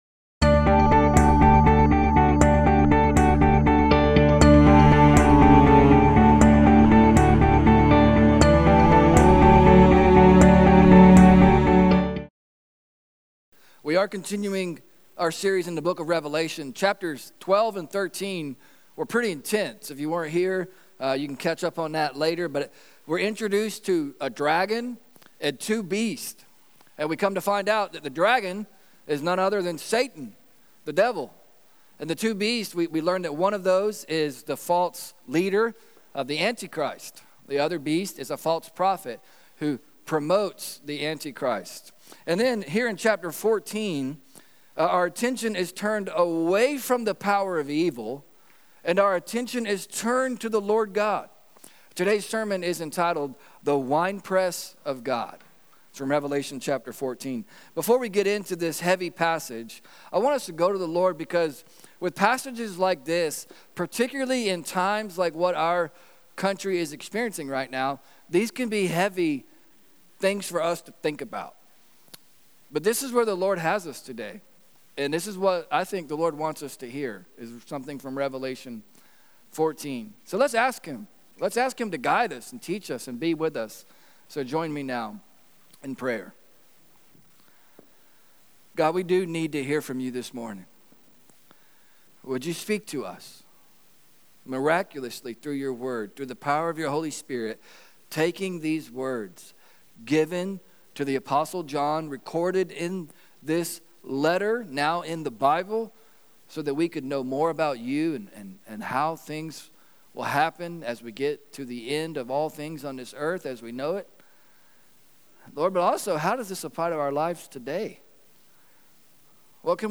In Chapter 14, the attention is turned away from the powers of evil onto the power of God. Today’s sermon is entitled “The Winepress of God.”